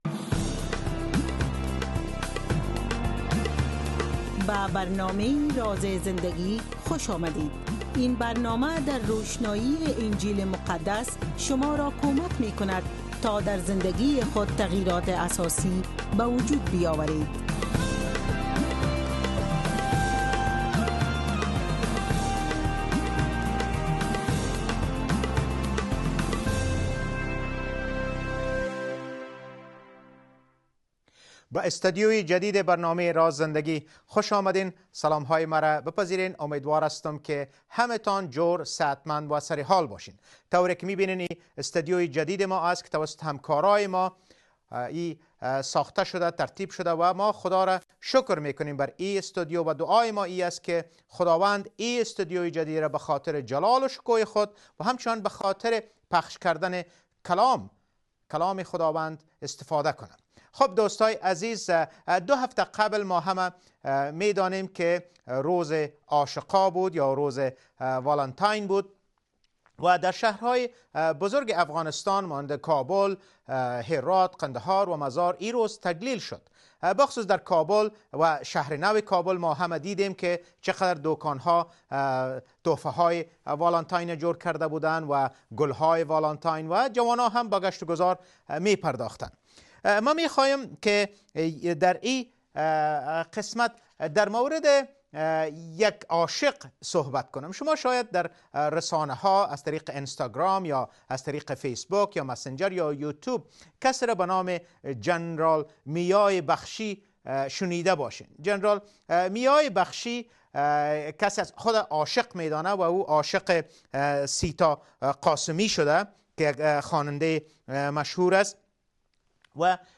This is the first episode of our programme in our new studio!